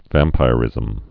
(vămpīr-ĭzəm)